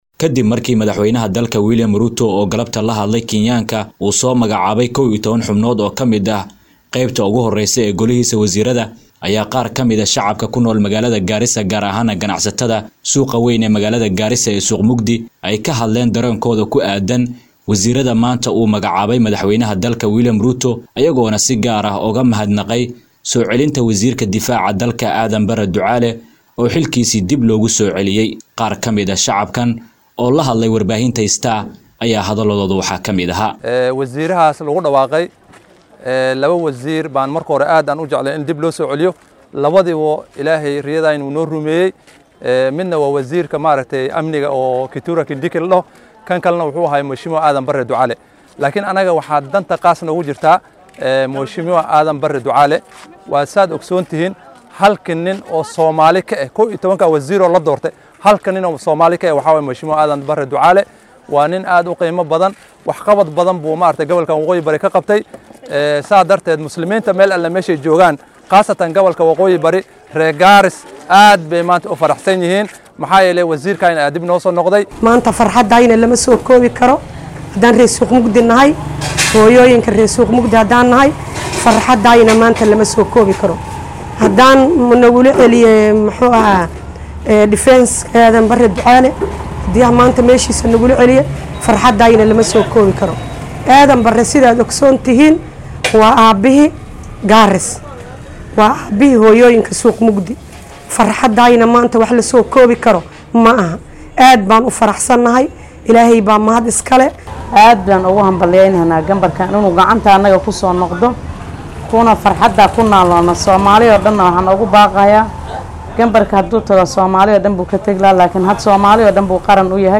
DHAGEYSO:Qaar ka mid ah shacabka Garissa oo ka hadlay dareenkooda ku aadan wasiirrada la soo magacaabay